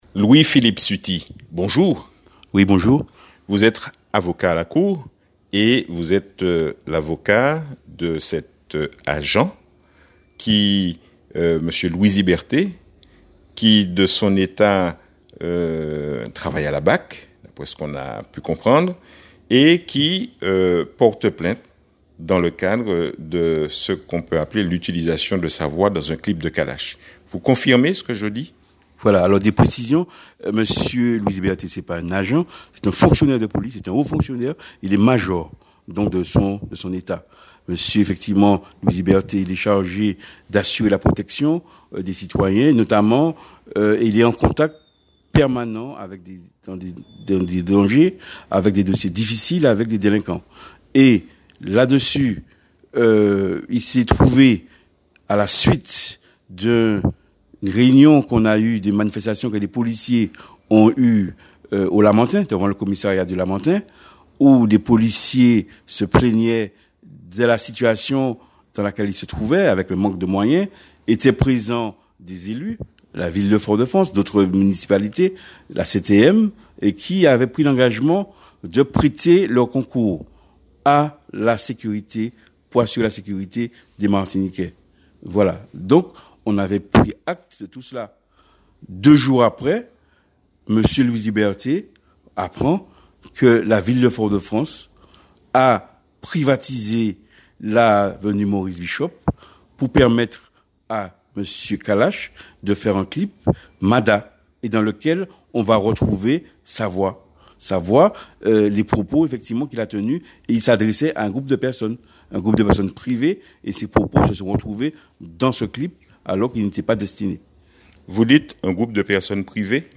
L'interview de son avocat.